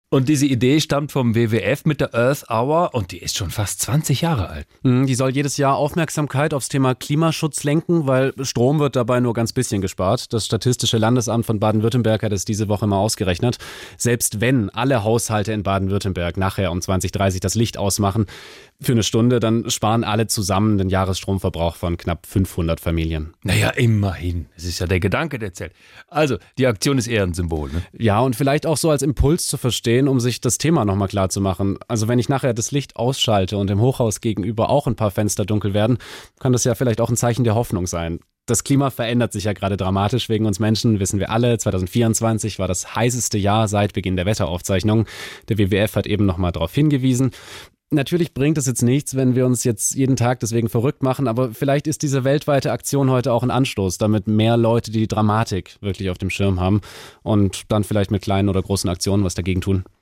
Nachrichten „Earth-Hour als Impuls für mehr Klimaschutz“